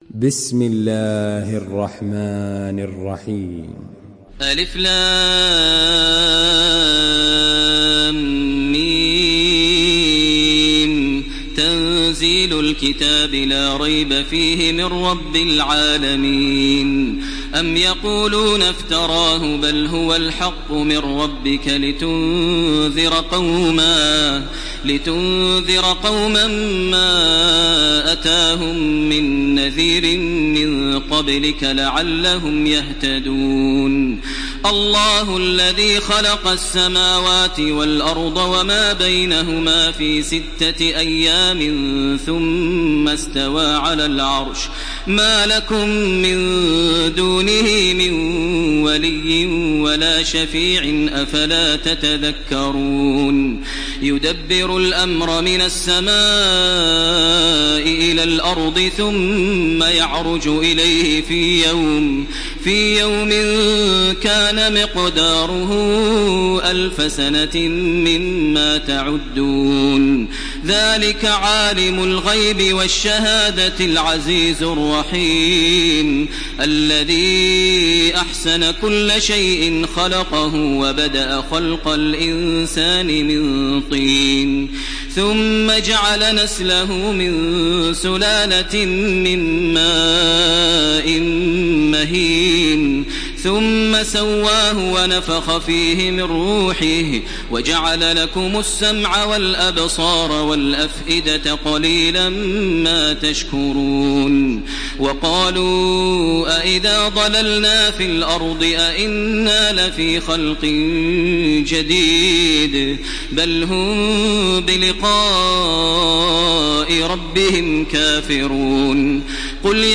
Surah আস-সাজদা MP3 by Makkah Taraweeh 1429 in Hafs An Asim narration.
Murattal